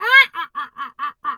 duck_2_quack_seq_05.wav